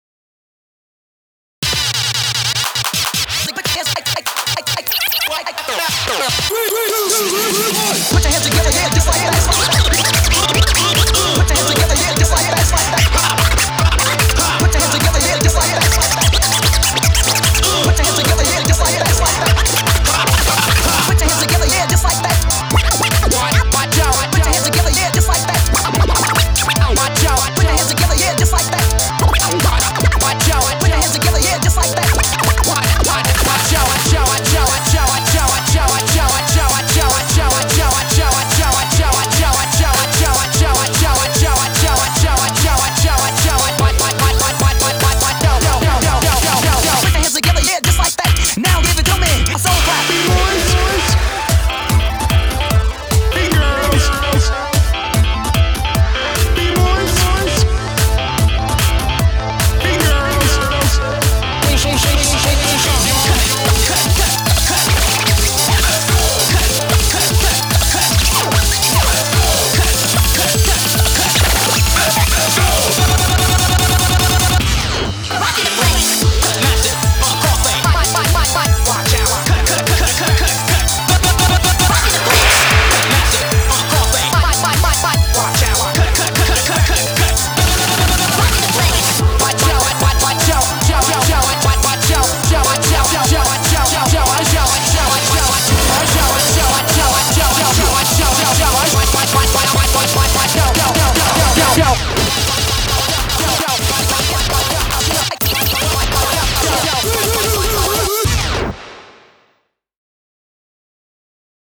BPM148
Audio QualityPerfect (High Quality)
Watch out for those mad scratches!